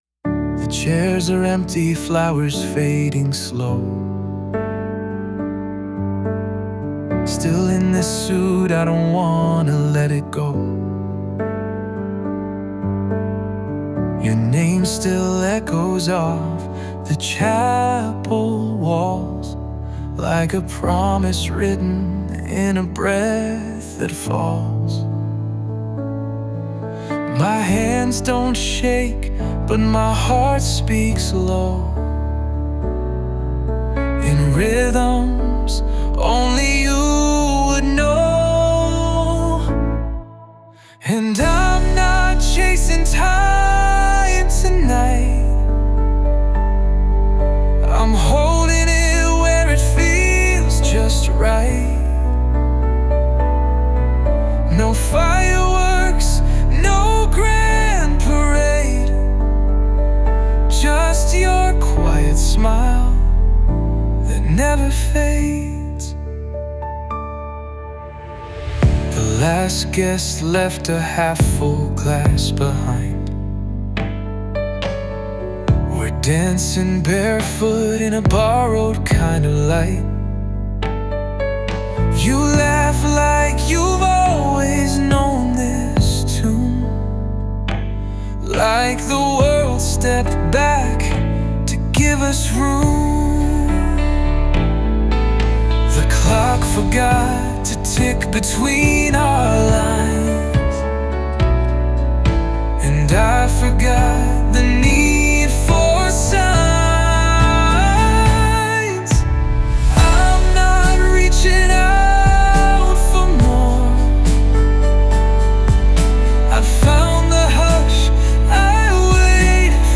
洋楽男性ボーカル著作権フリーBGM ボーカル
男性ボーカル洋楽洋楽 男性ボーカルエンドロール披露宴BGM退場バラード静か切ない優しい
男性ボーカル（洋楽・英語）曲です。